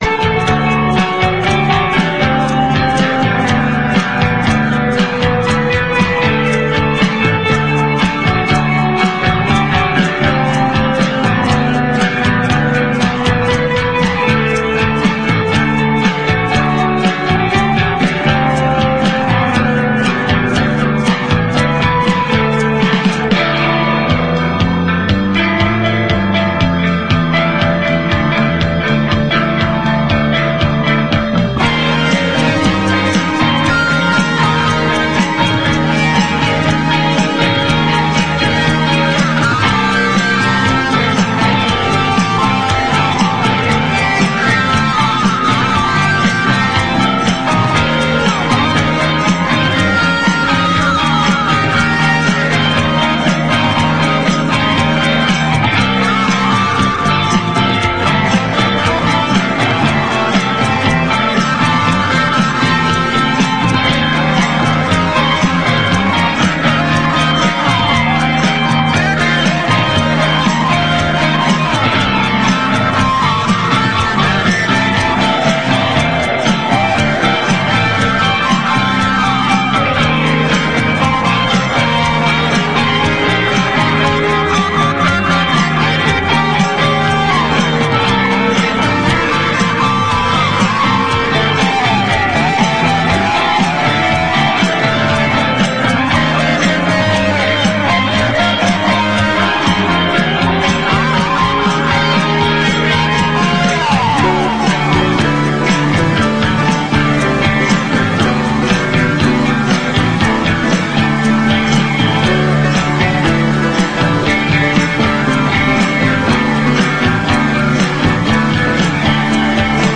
strange analogue adventures
indie pop